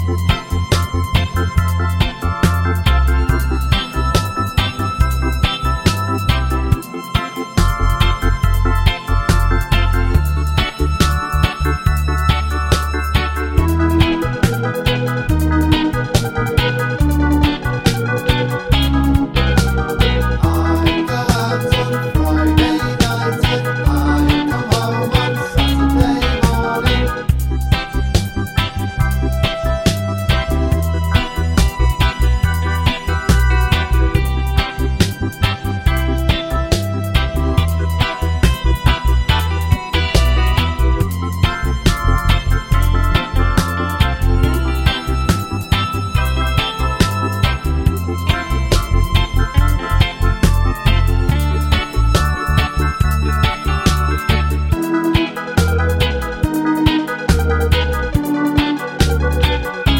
no Backing Vocals Ska 3:31 Buy £1.50